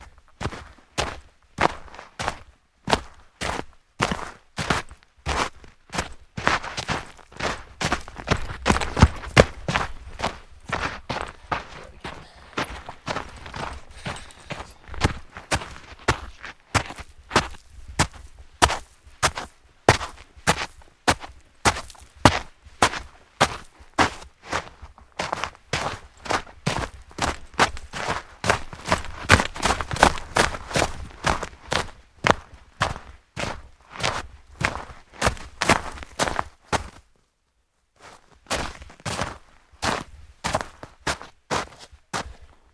冬天 " 脚步声 靴子正常包装的雪地上和下山的快速步骤和挖掘爬回的齿轮响声
描述：脚步声靴正常包装雪上下山快速步骤和挖掘爬回来与齿轮rattles.flac